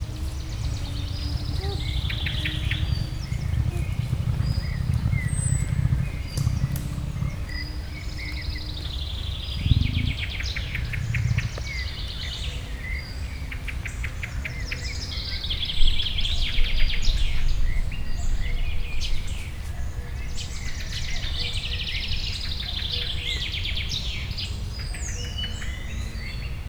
Directory Listing of //allathangok/miskolcizoo2018_professzionalis/egypupu_teve/
halkmorgas_miskolczoo0026.WAV